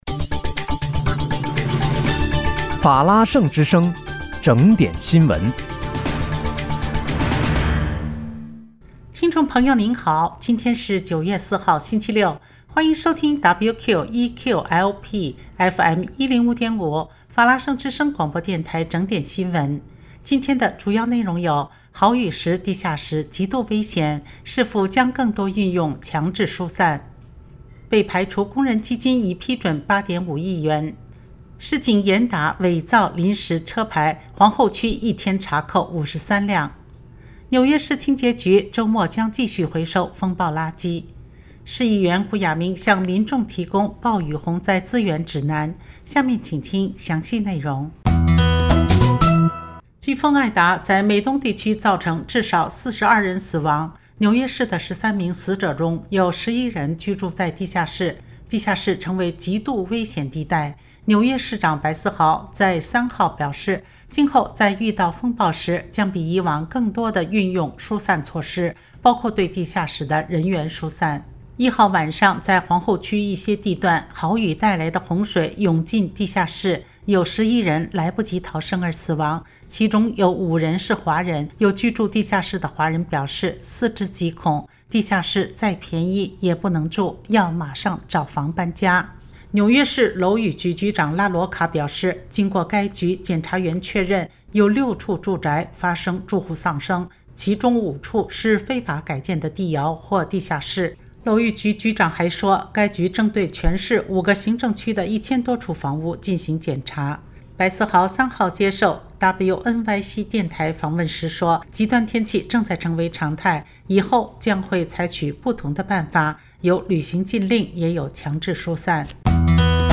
9月4日（星期六）纽约整点新闻